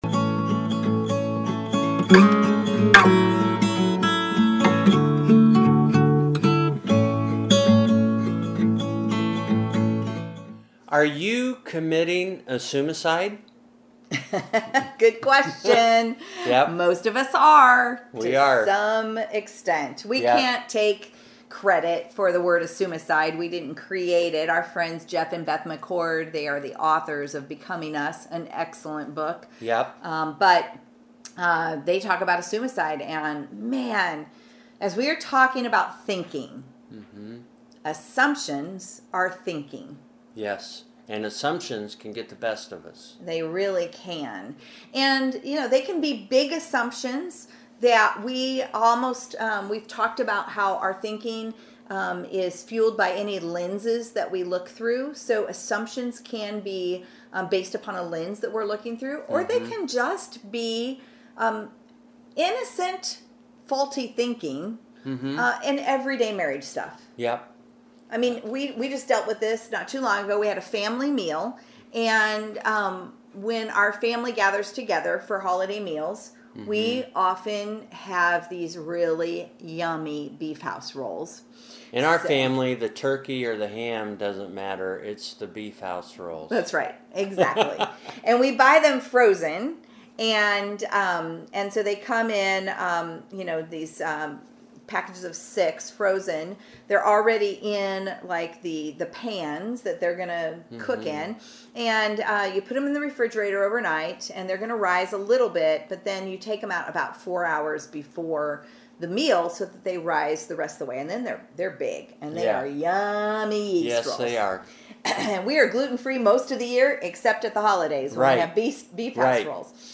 In this Dive Deep Interview